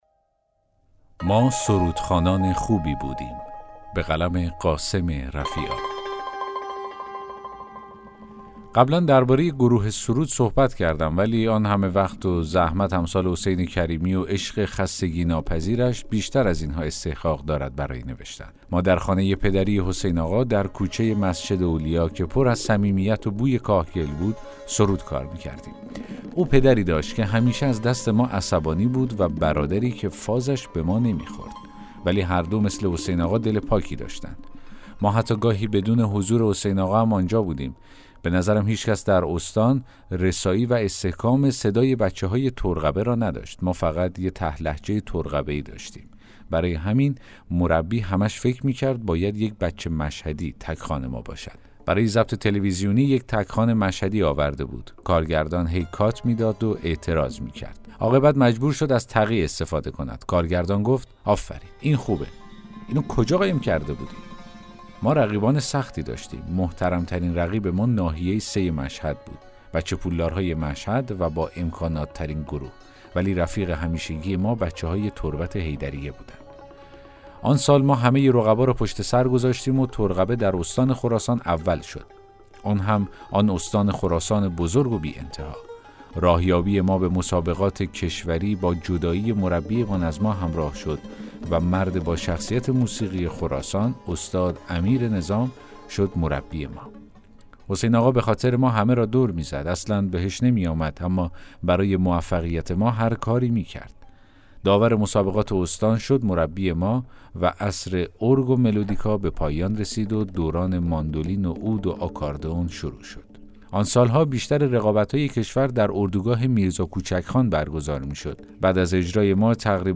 داستان صوتی: ما سرودخوانان خوبی بودیم!